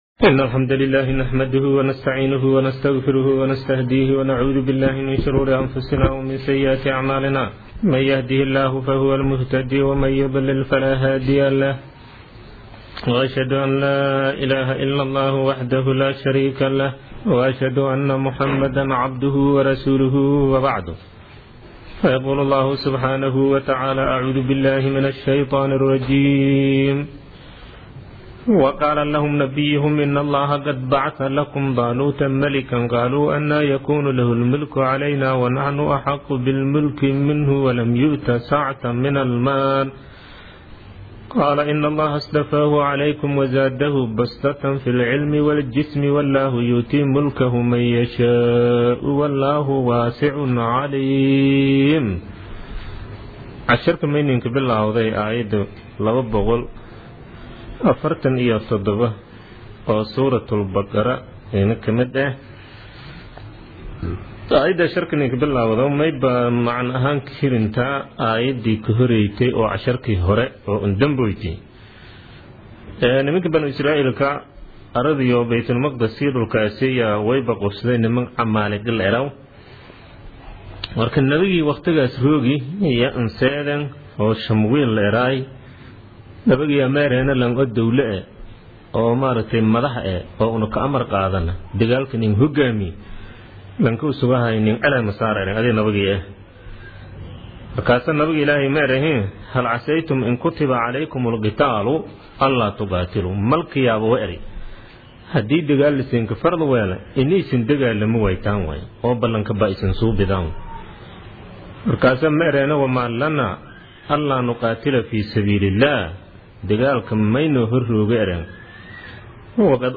Casharka Tafsiirka Maay 32aad